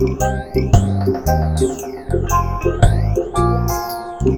PHASERLOOP-R.wav